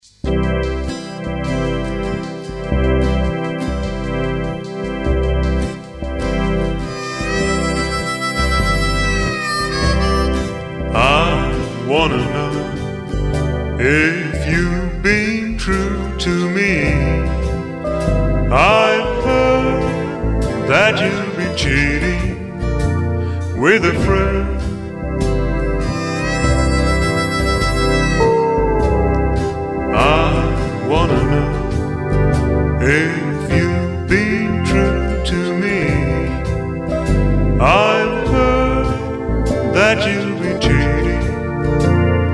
Très très triste, mais d'une beauté sans adjectif.